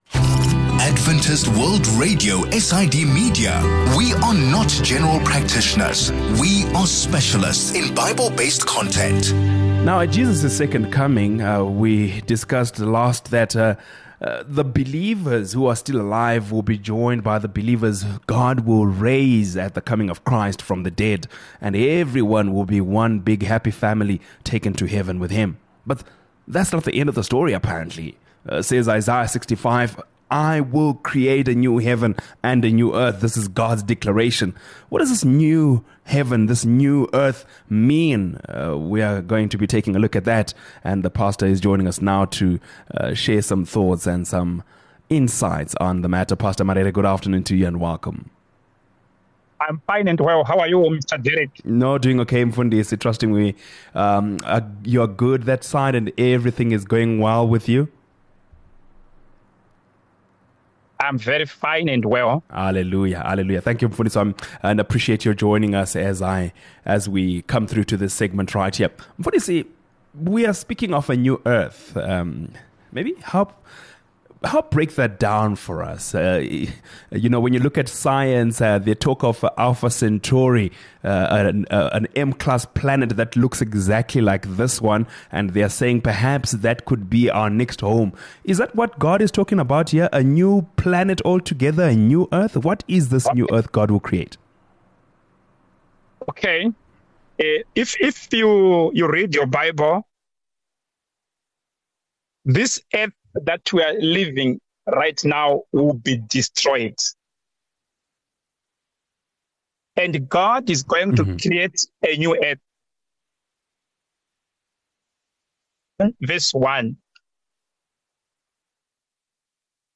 In this conversation, we’re going to learn about the new earth.